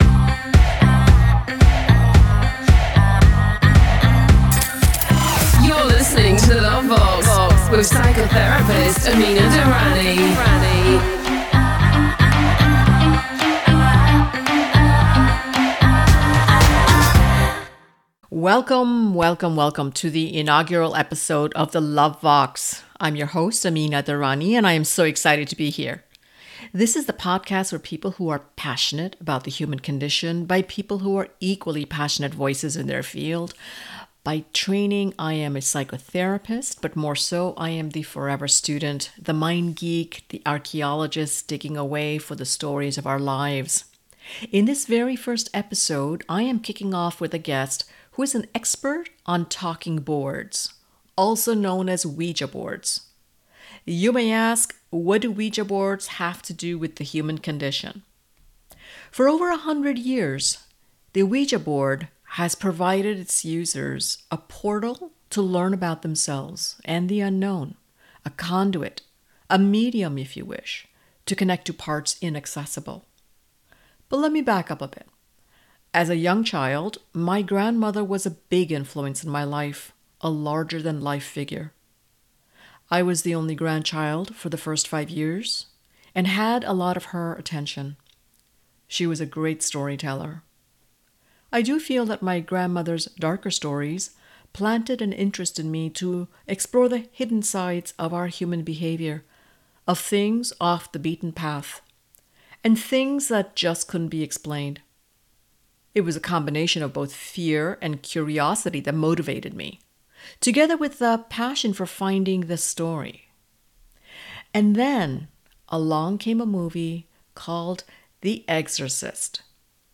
Through our conversation, we explore how the Ouija's origins have influenced societal attitudes towards spirituality and its role as a conduit to the unknown.